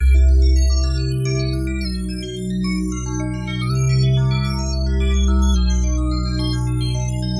标签： 环境 循环 低音 科学
声道立体声